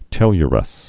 (tĕlyər-əs, tĕ-lrəs)